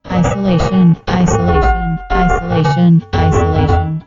The attached is heavily dynamics compressed, as it was a bit peaky otherwise.